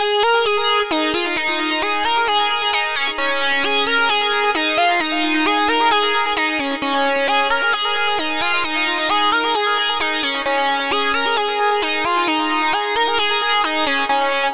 Brightest Fuzz BPM 132.wav